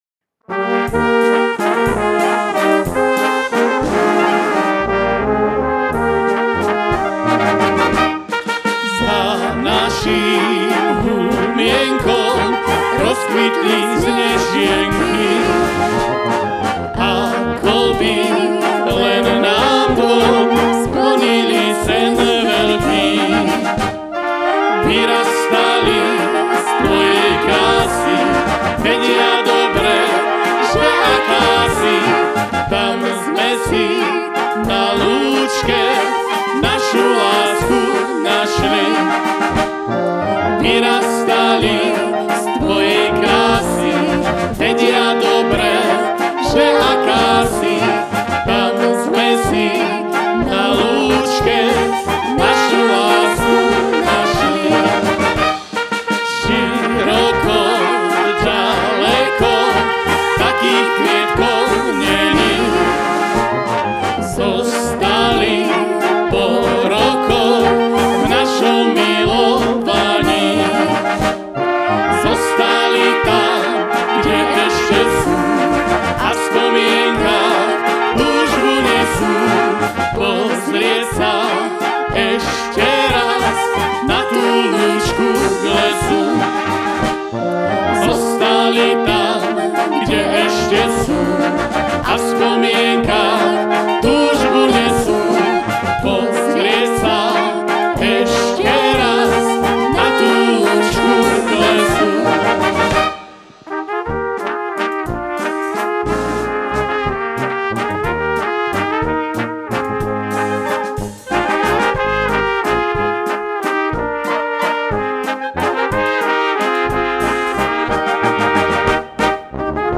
valčík